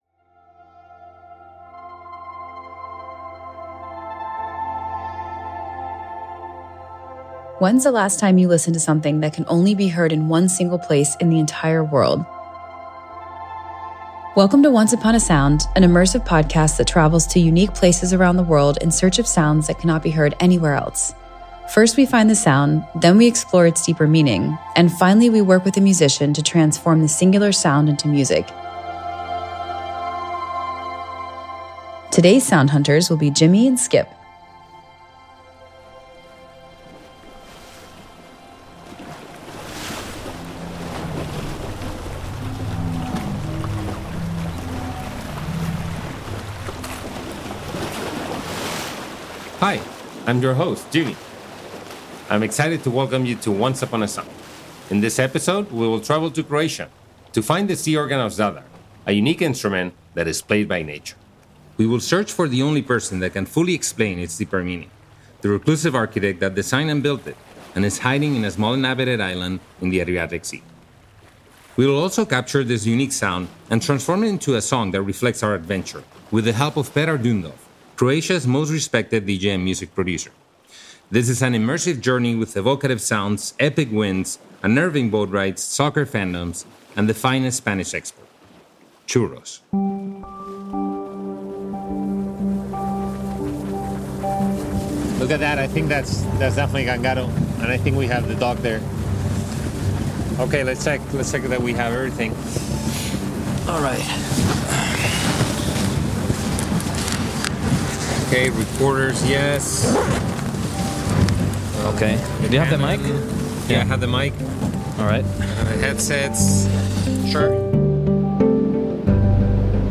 This is an adventure of epic winds, conflicting soccer fandoms, remote islands frozen in time, unnerving boat rides, and a myriad of evocative sounds as old and venerable as the Mediterranean Sea.